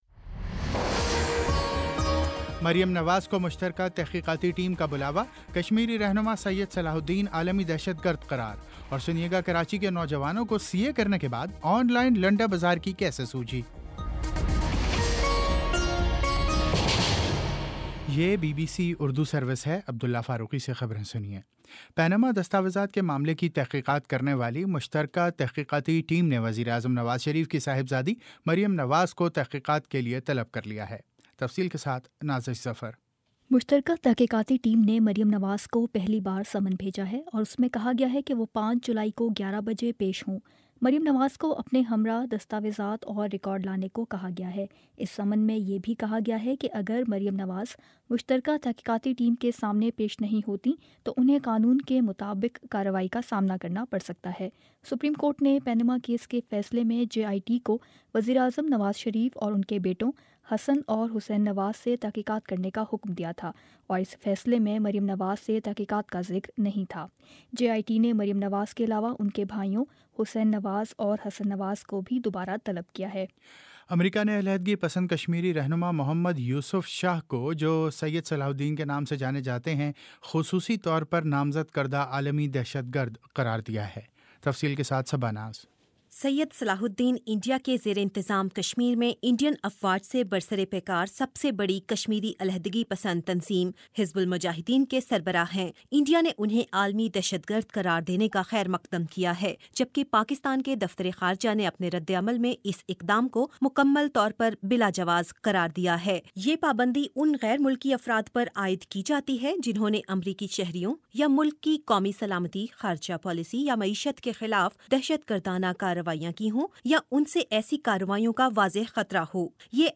جون 27 : شام پانچ بجے کا نیوز بُلیٹن